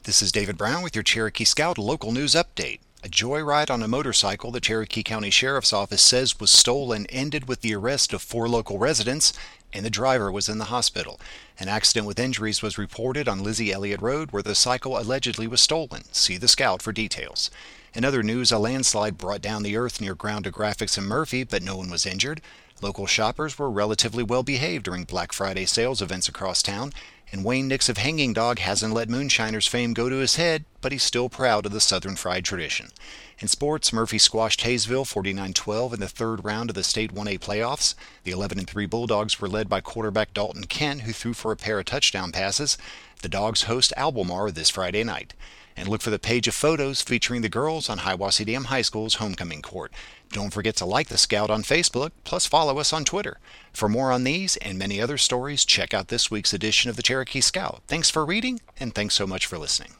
Cherokee Scout News Update